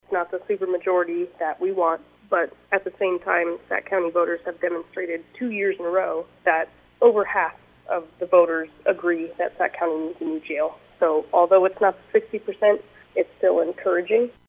SAC COUNTY SHERIFF KATIE STANGE SAYS IT WAS TOUGH TO SEE THE MEASURE COME SO CLOSE TO PASSING.